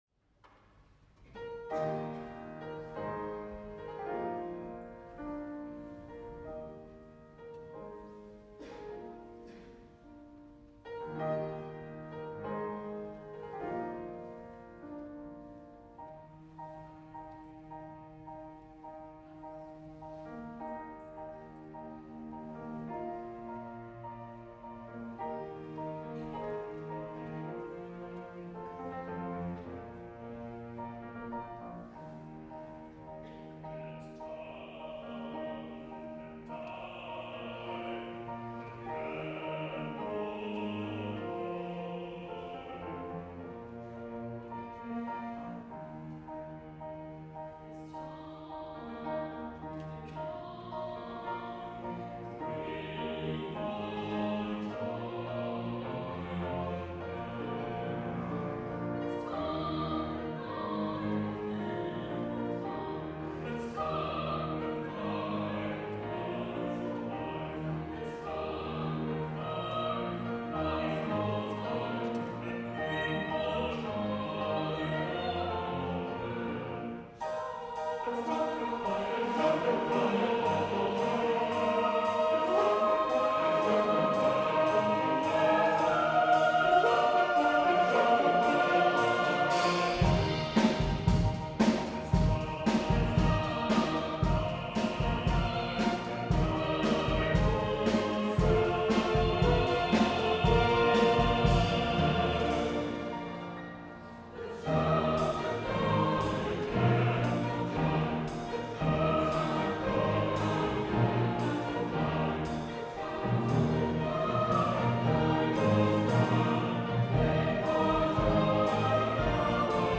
Organ
St Eunan's Cathedral of Letterkenny, Ireland 21.10.2022: